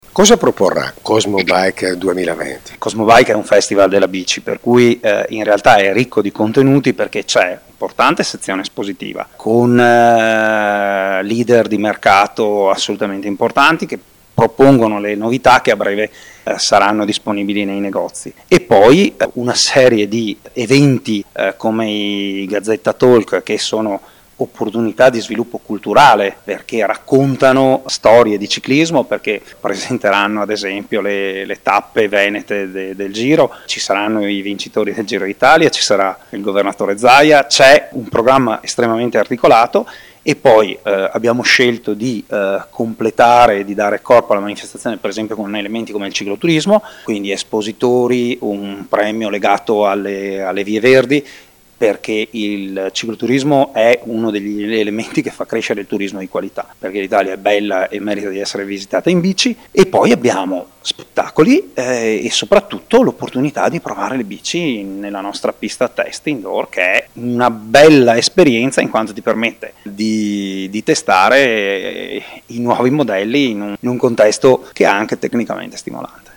Interviste a cura del corrispondente